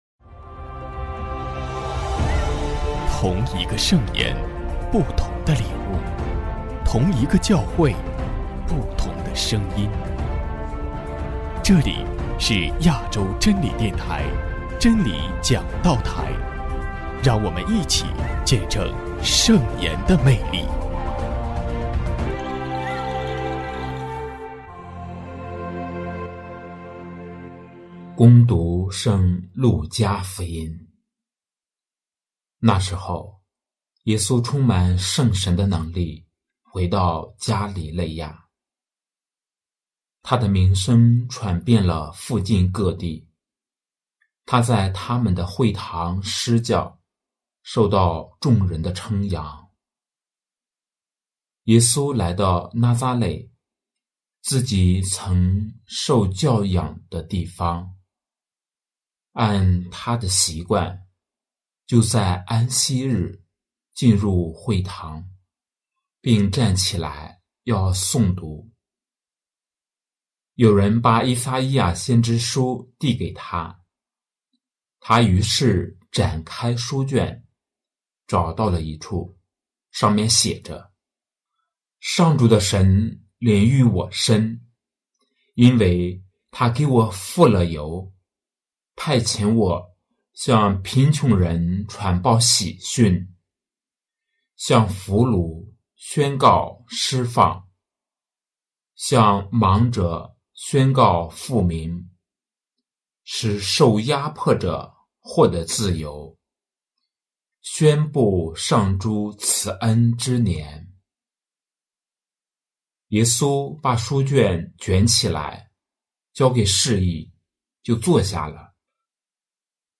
【真理讲道台】| 给人希望和温暖——农历春节弥撒（甲年常年期第三主日）